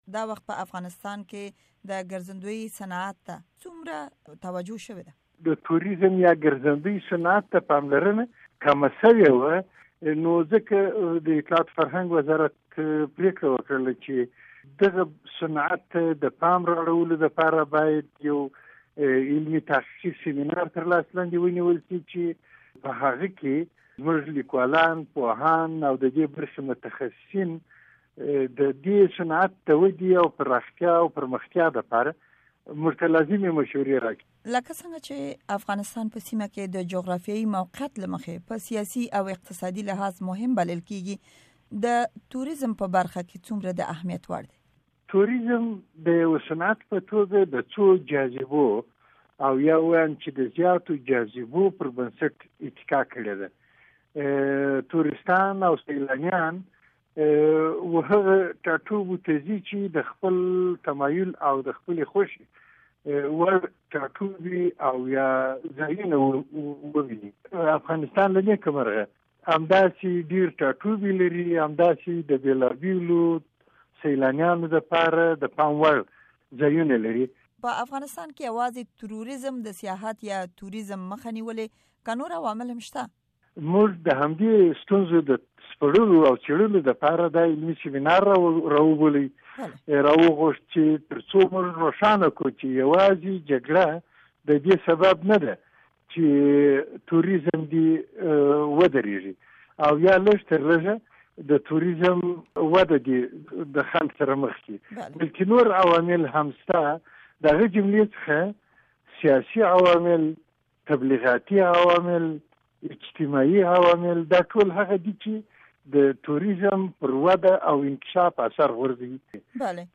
مرکې